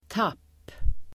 Uttal: [tap:]